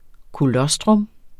Udtale [ koˈlʌsdʁɔm ]